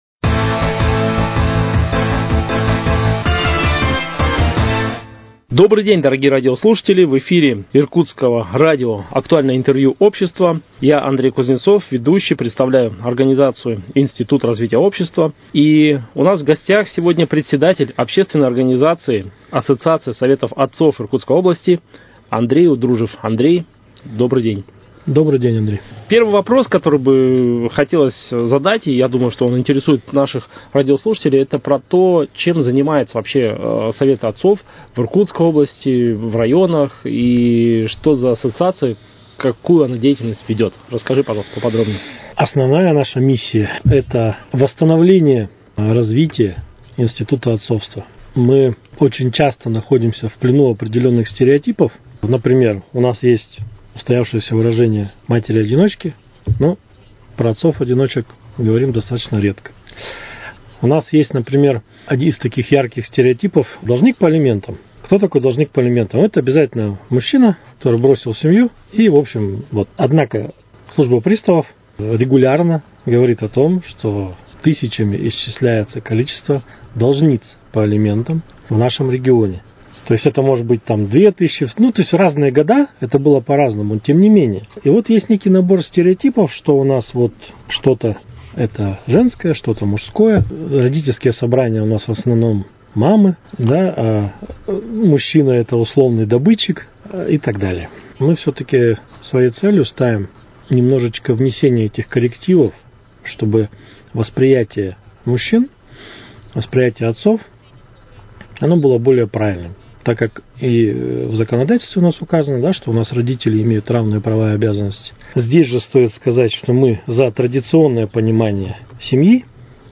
Актуальное интервью: О роли мужчины в семье